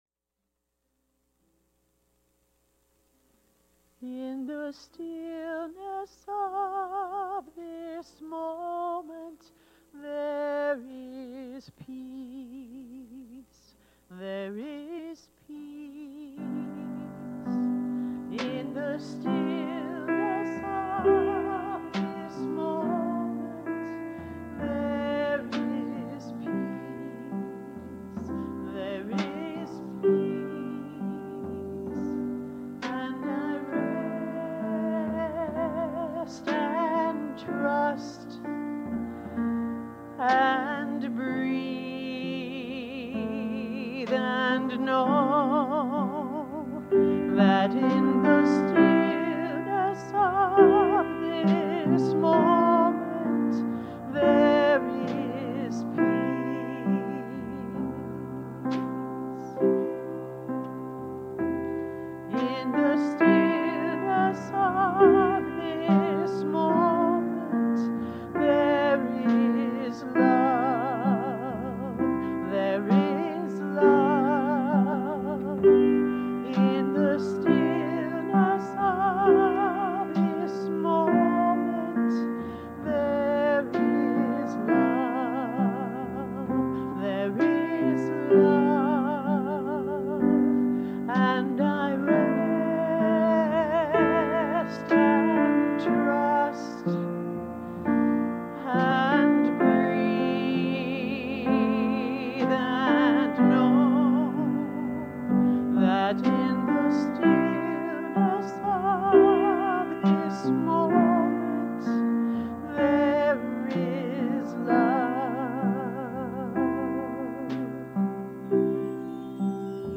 The audio recording below the video clip is an abbreviated version of the service. It includes the Meditation, Message, and Featured Song.